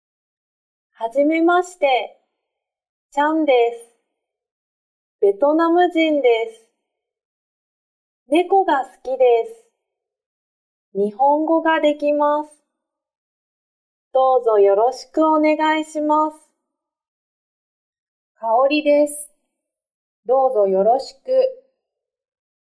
Nghe hội thoại giữa Trang và Kaori